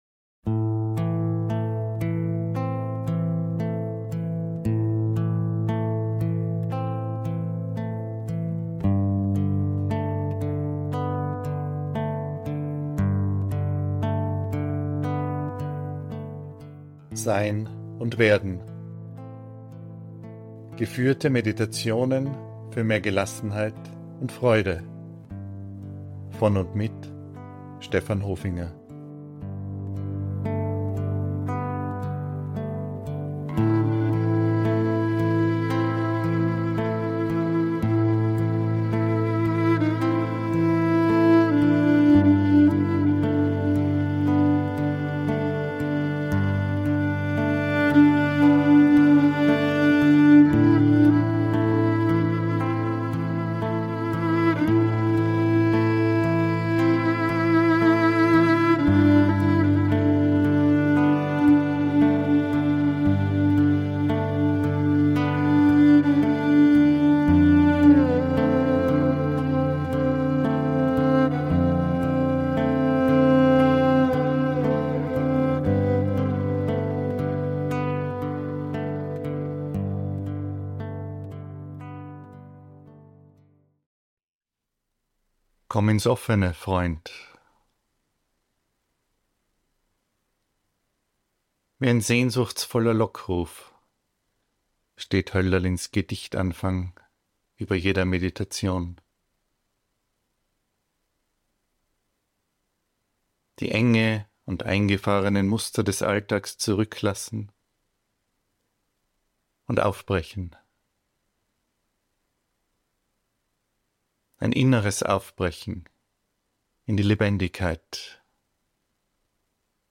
Wie ein sehnsuchtsvoller Lockruf steht Hölderlins Gedichtanfang über jeder Meditation. Die Enge und die eingefahrenen Muster des Alltags zurücklassen und aufbrechen. Ein inneres Aufbrechen in die Lebendigkeit im stillen Sitzen.